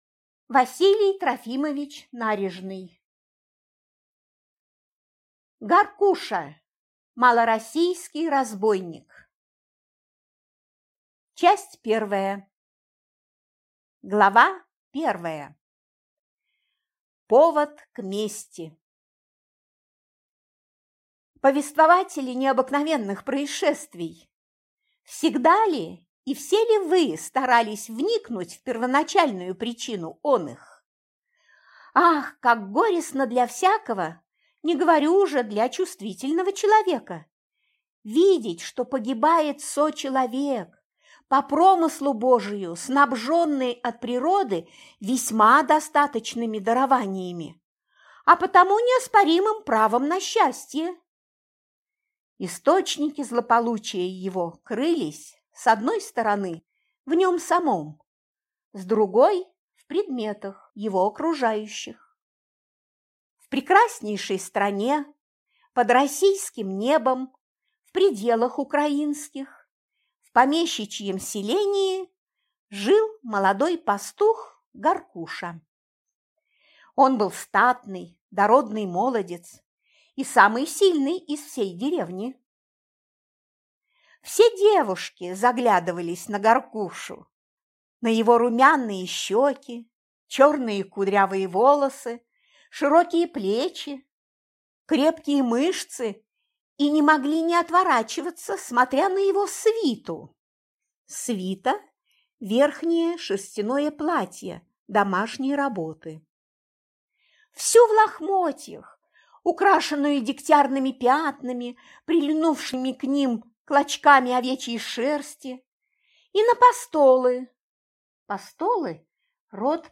Аудиокнига Гаркуша, малороссийский разбойник | Библиотека аудиокниг